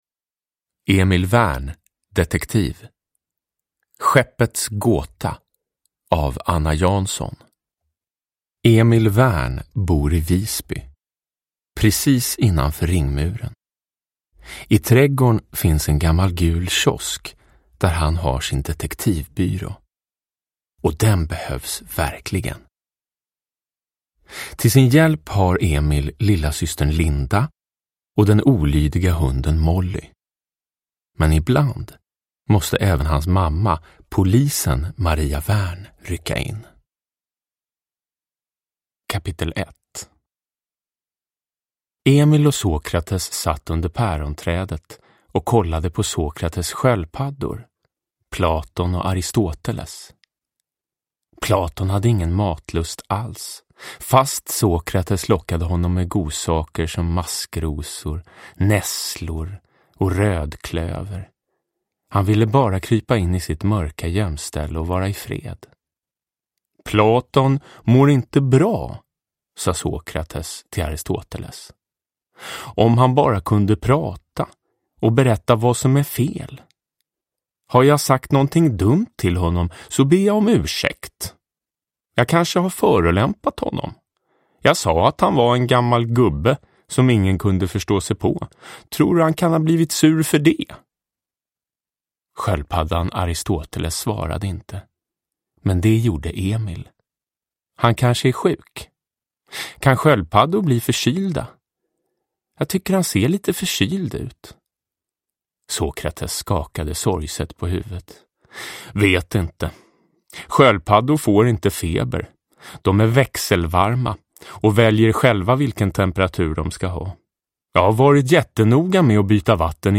Skeppets gåta – Ljudbok
Uppläsare: Jonas Karlsson